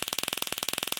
electric.ogg